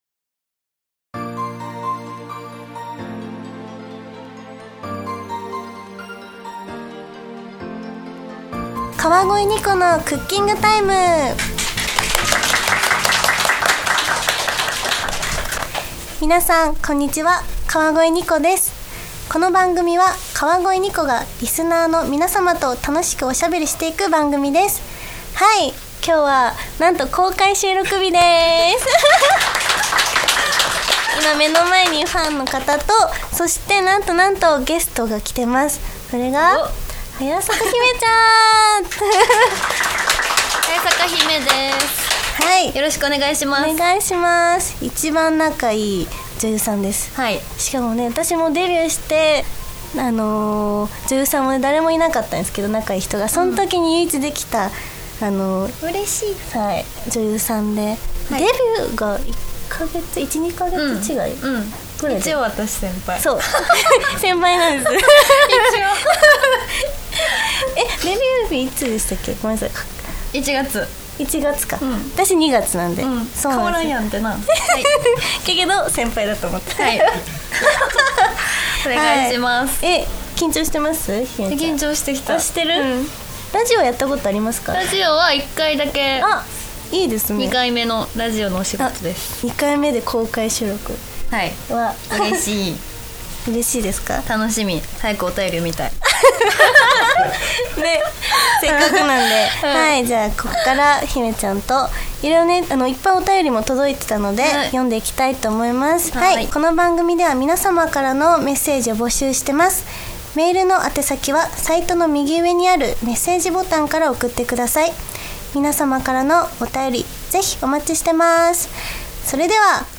7月に番組初の公開収録が開催されました！